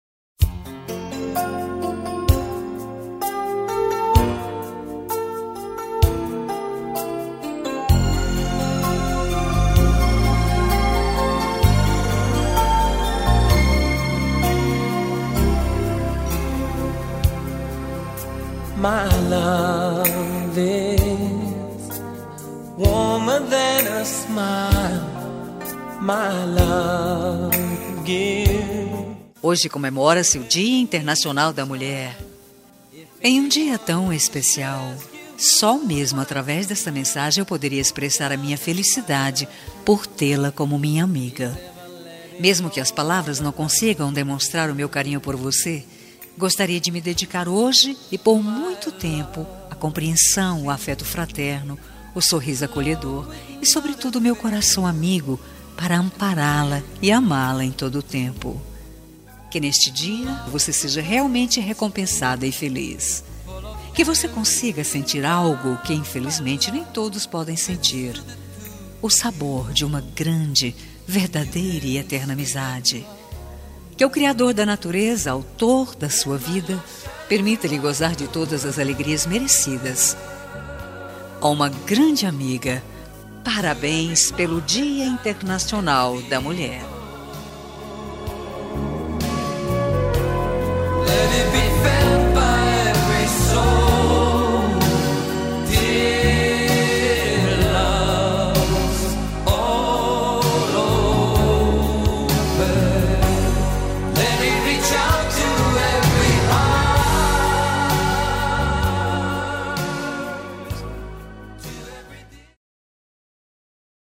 Dia das Mulheres Para Amiga – Voz Feminina – Cód: 5350